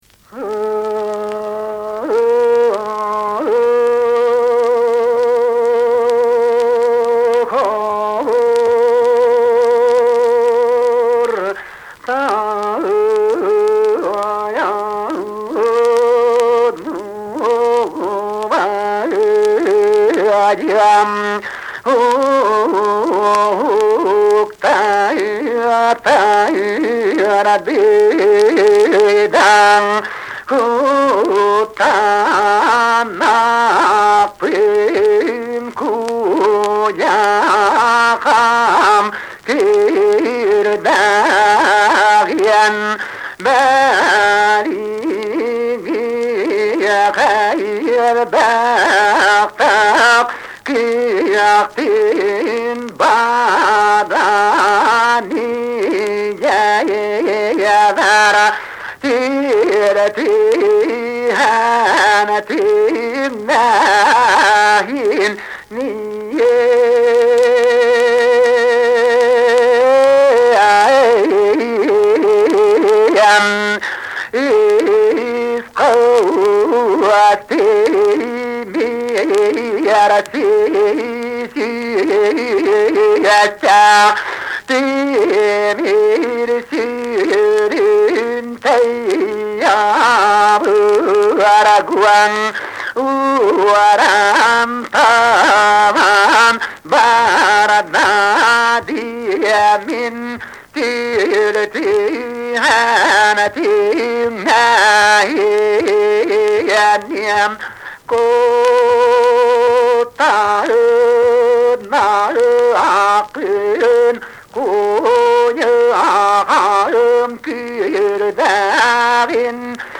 Якутский героический эпос "Кыыс Дэбилийэ"
Песня Хачылаан Куо из олонхо "Урун Уолан".